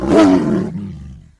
combat / creatures / tiger / he / attack3.ogg